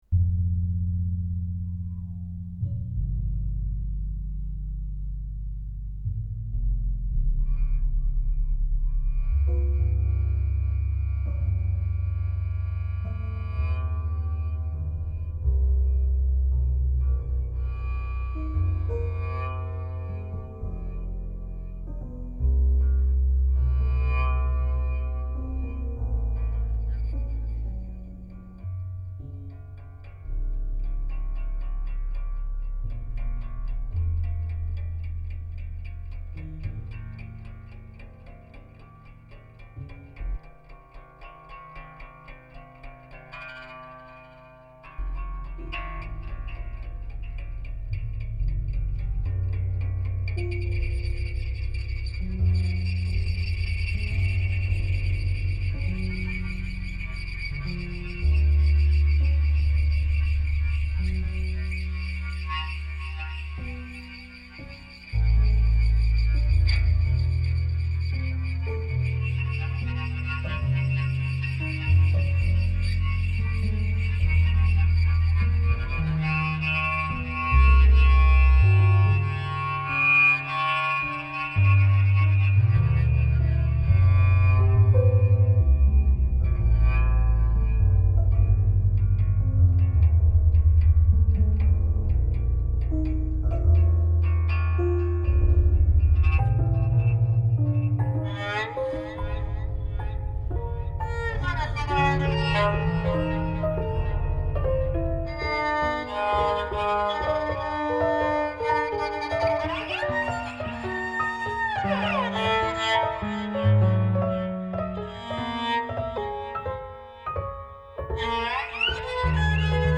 Con questa operetta (due ore intense e faticosissime) presentata al Teatro Eliseo di Nuoro ,